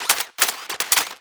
GUNMech_Reload_11_SFRMS_SCIWPNS.wav